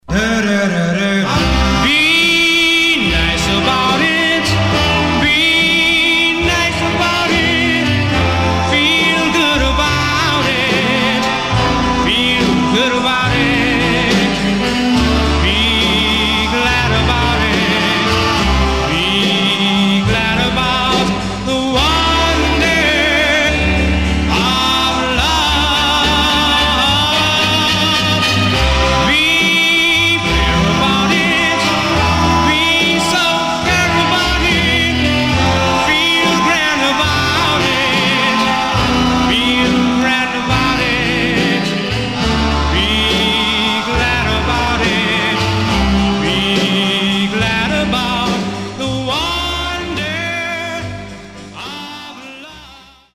Mono
Male Black Groups